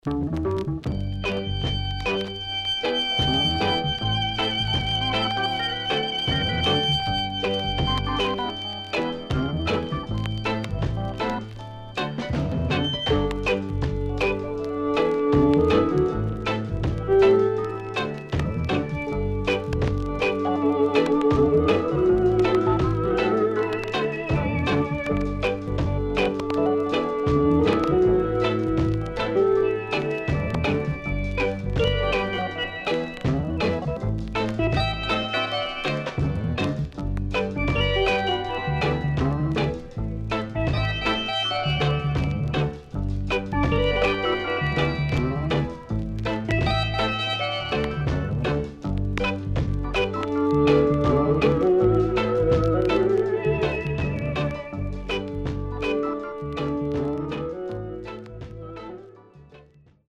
Nice Early Reggae.Skinheads
SIDE A:少しチリノイズ入ります。